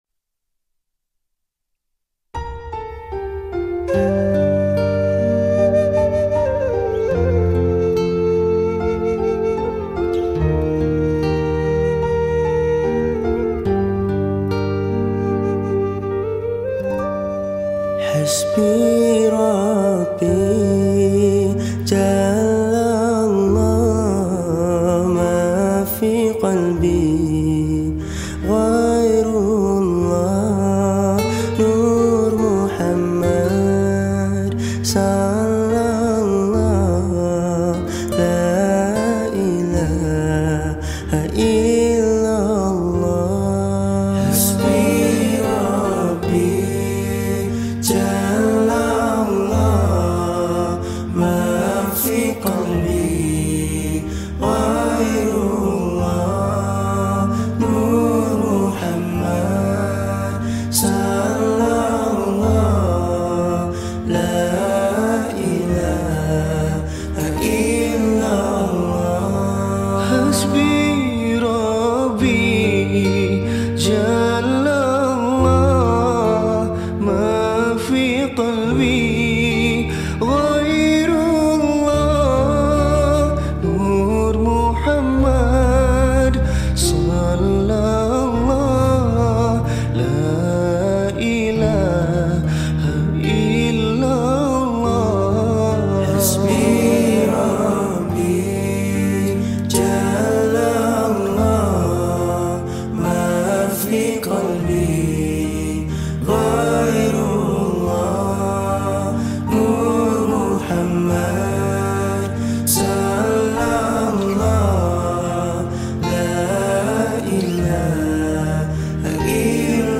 Nasyid Songs
Skor Angklung